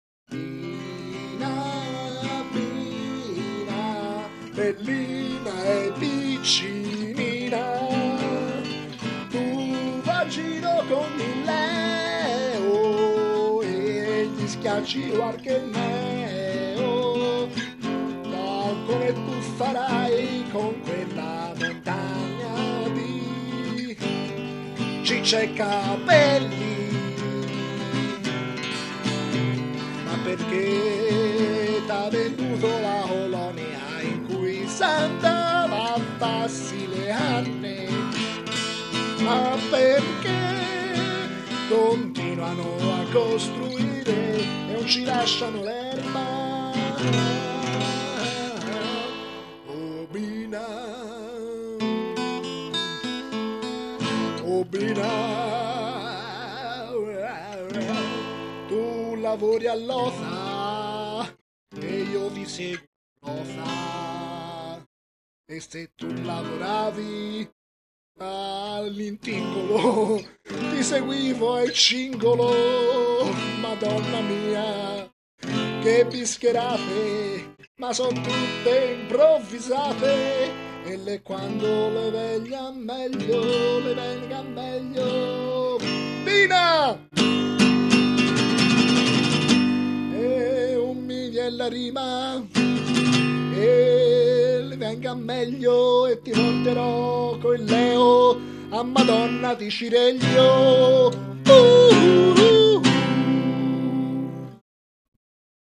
Una canzone improvvisata su una cara amica... i fatti narrati sono di fantasia (seee...)